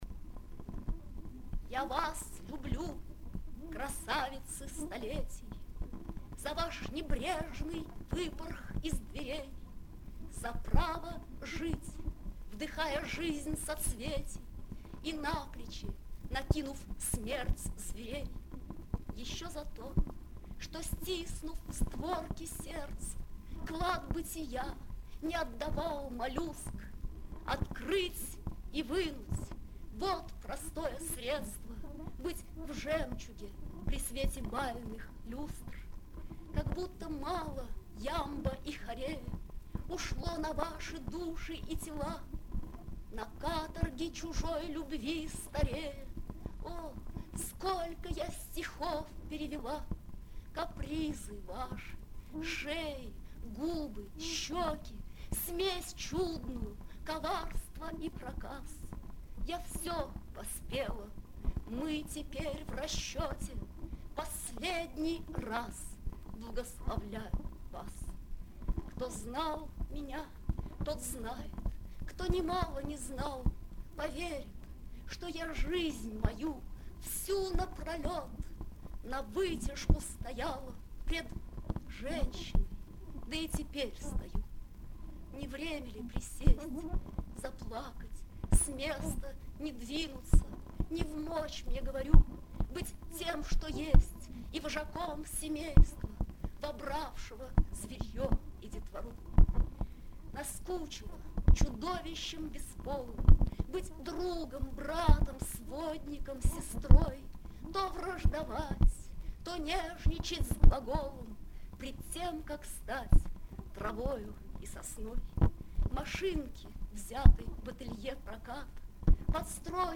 3. «Белла Ахмадулина – Я вас люблю, красавицы столетий (читает автор)» /
bella-ahmadulina-ya-vas-lyublyu-krasavitsy-stoletij-chitaet-avtor